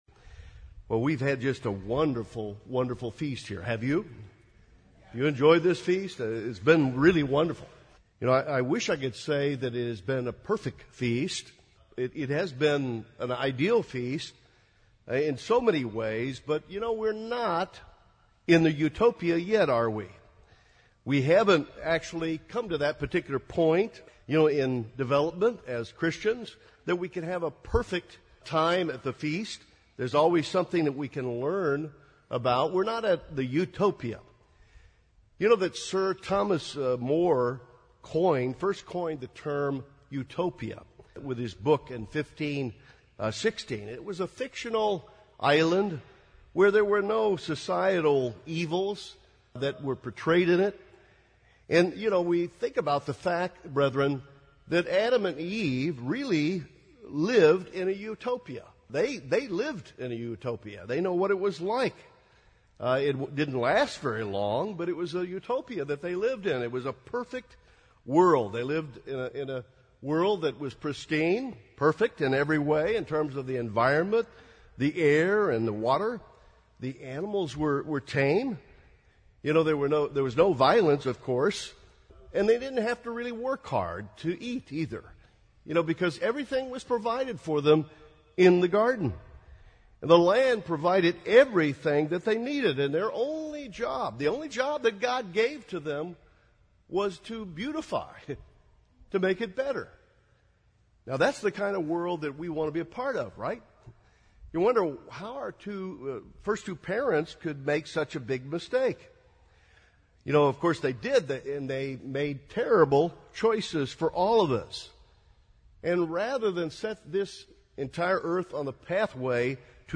This sermon was given at the Oceanside, California 2016 Feast site.